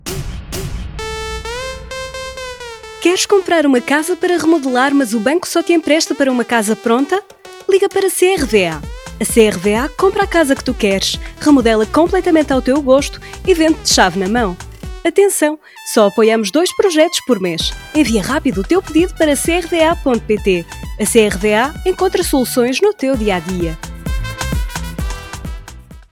Portuguese Female Voiceover
Radio commercial
commercial_portuguesefemalevoiceover.mp3